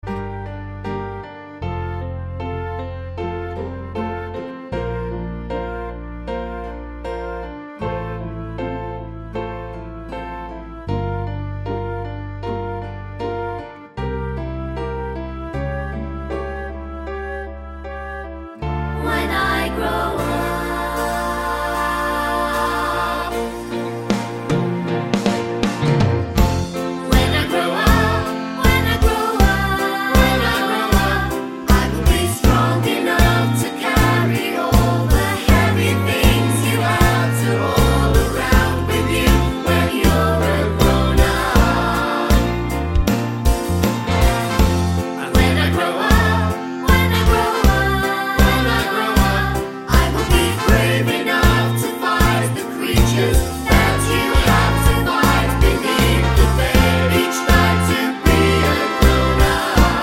for duet Musicals 3:39 Buy £1.50